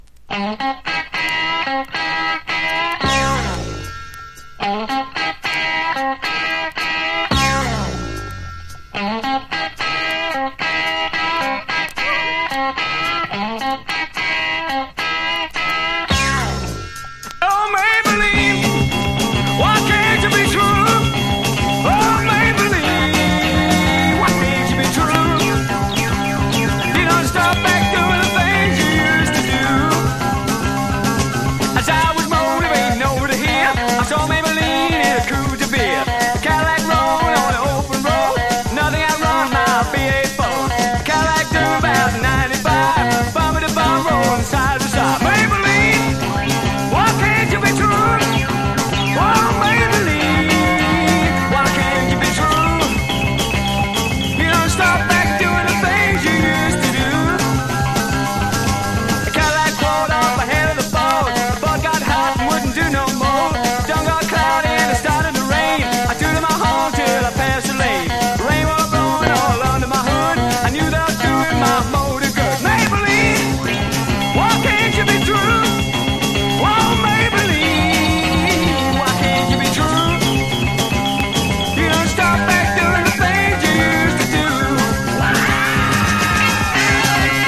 ブルースロックをベースにしながらもツインギターが暴れるブギーナンバーが多く、独特の個性を放っています！